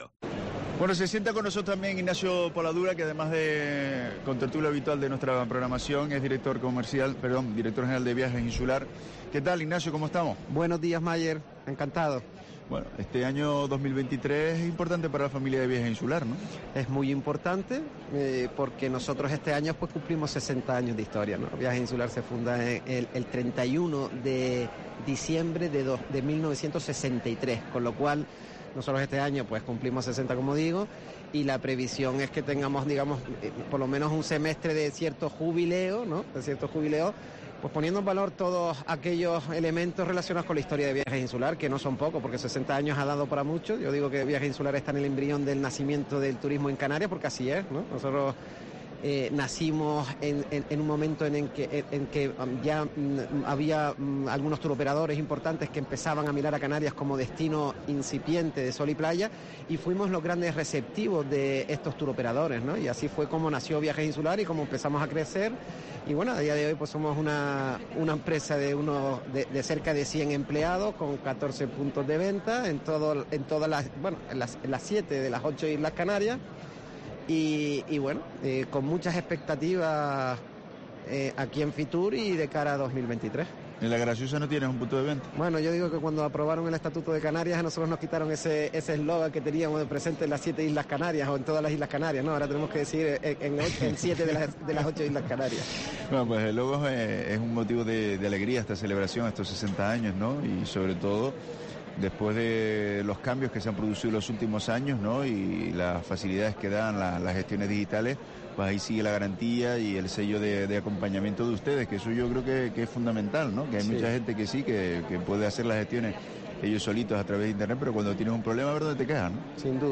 En el marco de la programación especial que COPE Canarias ha emitido estos días desde FITUR 2023